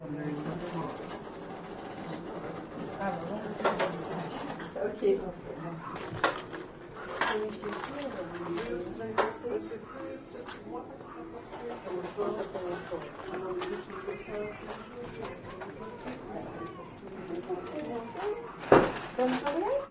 法国田野录音 " Hyeres商店爵士乐
描述：慵懒的爵士乐声构成了一家法国商店内的一般声音和声音的背景。
标签： 气氛 气氛 现场记录 法国 音乐 语音 语音
声道立体声